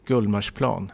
pronunciation) is a place in Johanneshov, Stockholm.
Sv-Gullmarsplan.ogg.mp3